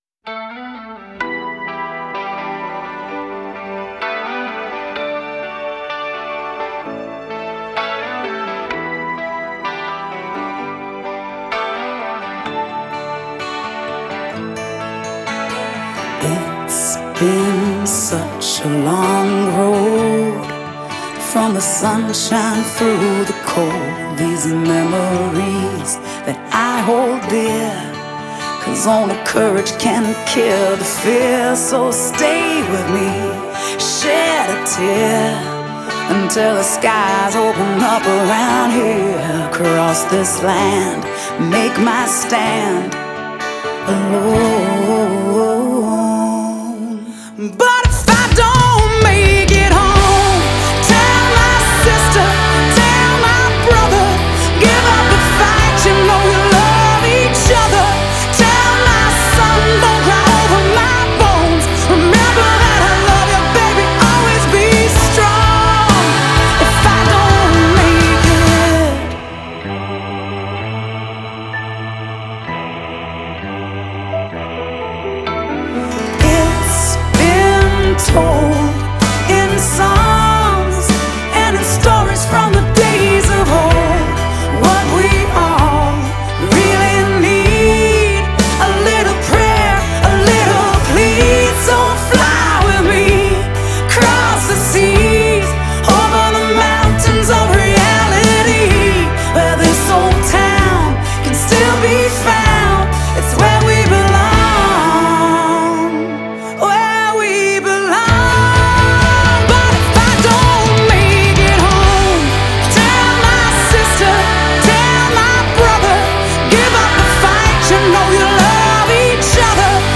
Играет хард-рок.